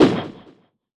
whiff.wav